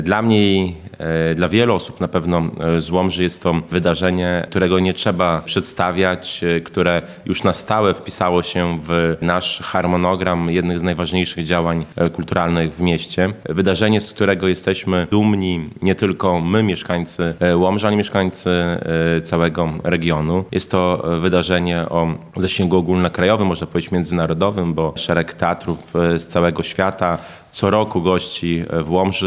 Podczas dzisiejszej (29.05) konferencji prasowej prezydent miasta, Mariusz Chrzanowski podkreślał, że to niezwykłe wydarzenie teatralne w mieście, które na stałe wpisało się w tradycje Łomży: